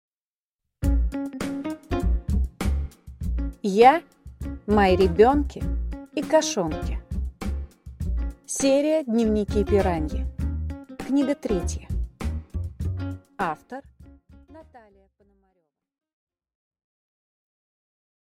Аудиокнига Я, мои ребенки и кошонки | Библиотека аудиокниг